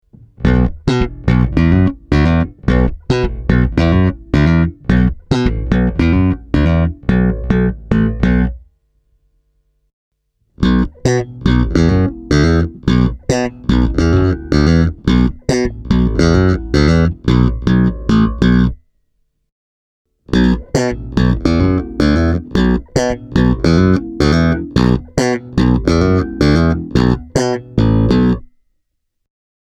Atakki ja dynamiikka ovat hyvässä kunnossa, ja sointi on vapaa häiritsevistä sivuäänistä tai resonansseista.
Tässä kolme esimerkkisoundia (järjestyksessä: kaulamikki – molemmat – tallamikki):
Mannedesign Newport 4T – plektralla